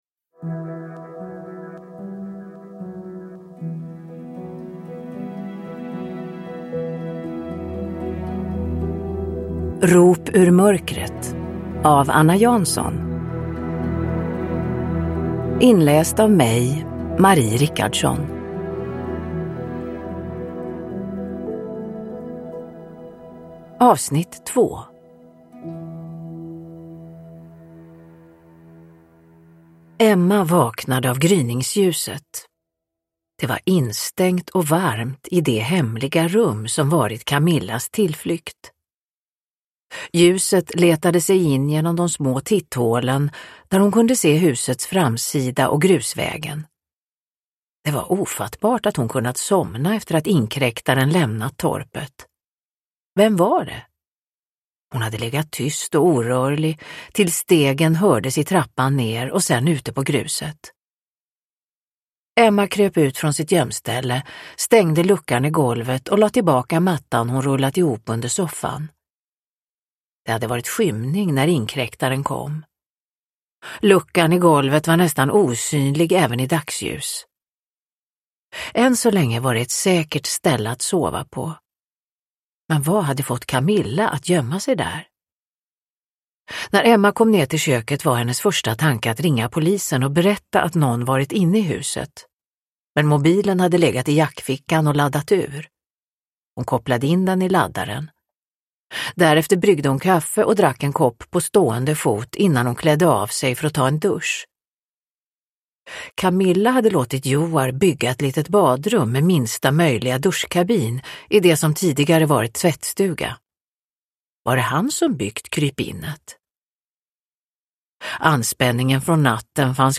Rop ur mörkret - 2 – Ljudbok – Laddas ner
Uppläsare: Marie Richardson